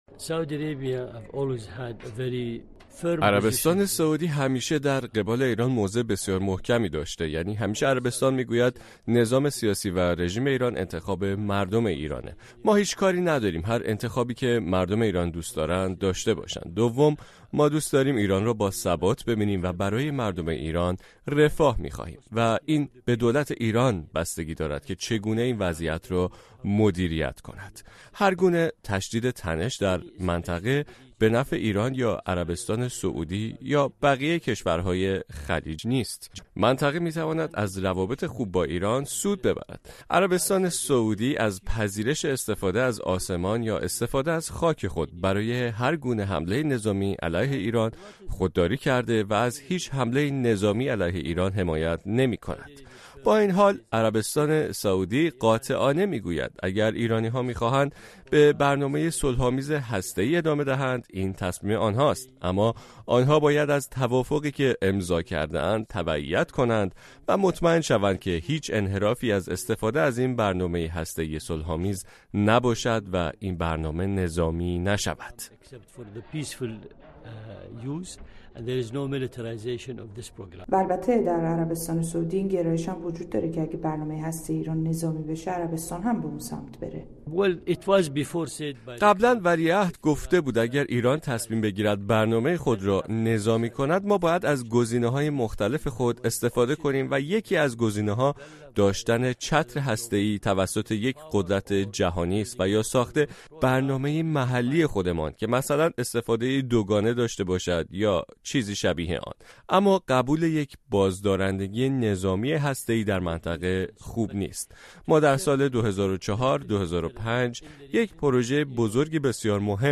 آیا عربستان می‌تواند بین ایران و آمریکا میانجی شود؟ گفت‌وگو